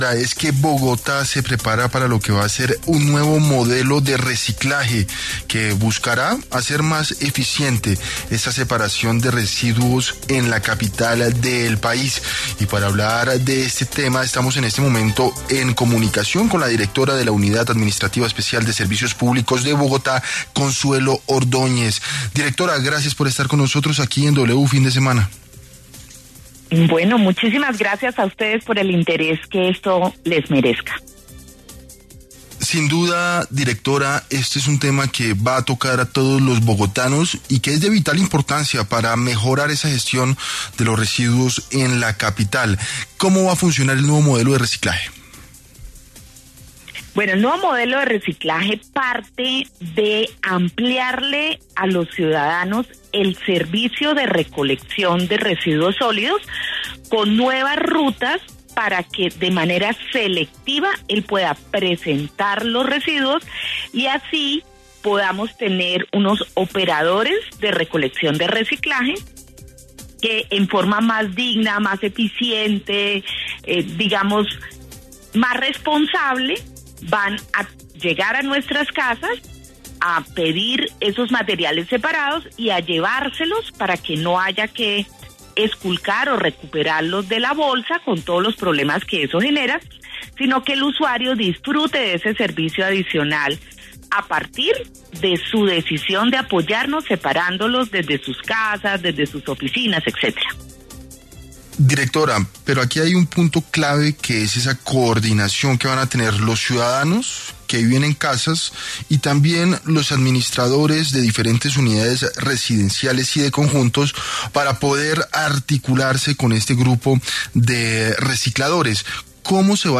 En diálogo con W Fin de Semana, la directora de la UAESP, Consuelo Ordóñez, entregó detalles del plan piloto que se llevará a cabo en Bogotá en relación al reciclaje.